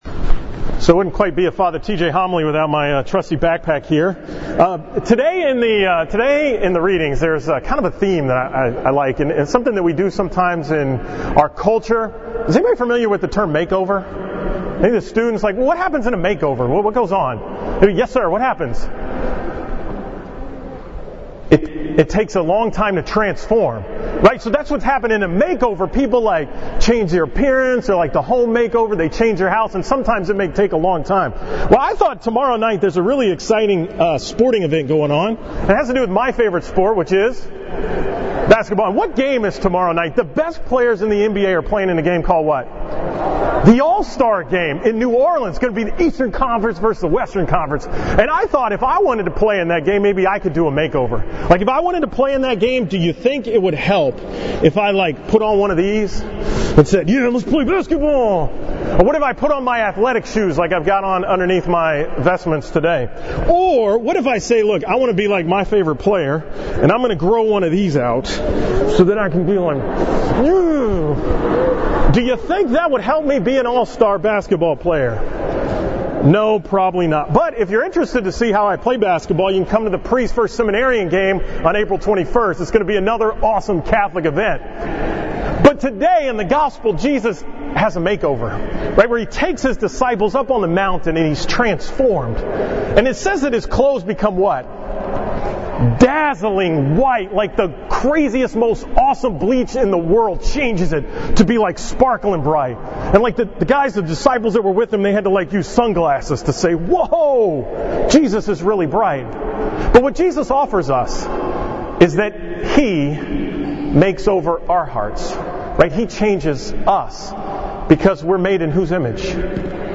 From the Steps for Students sunrise Mass at the Co-cathedral on February 18, 2017